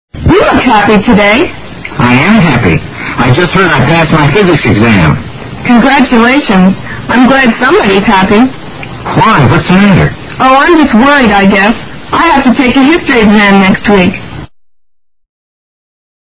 DIALOG 21
1）I am happy：注意重音在am上，以示强调。
3）I'm glad somebody's happy:somebody重读，显示说话者本人心里有些忧虑。